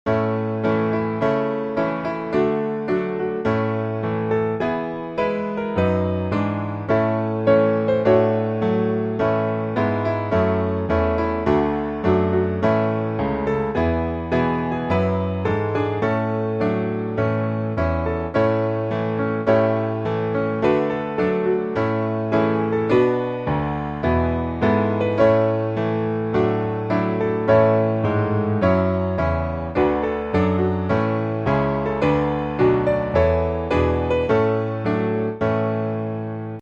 Gospel
A Majeur